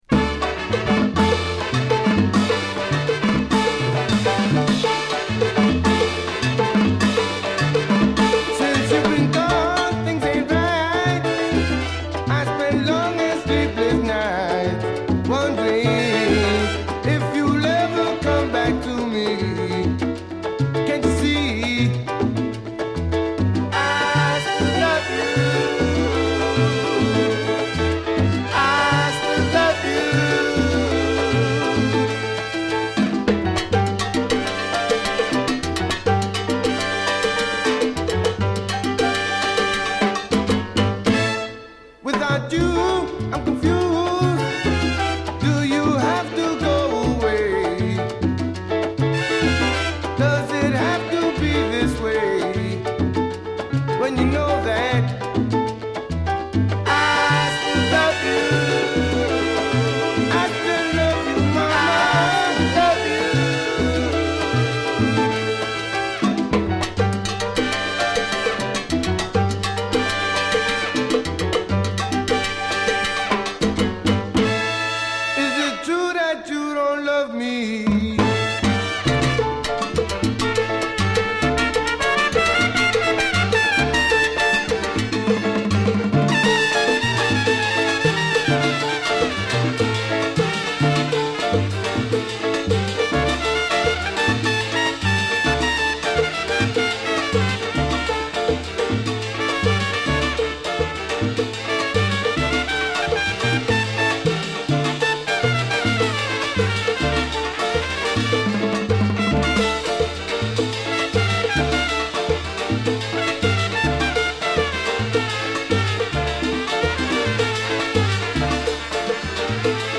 a song that starts out in English before shifting to Spanish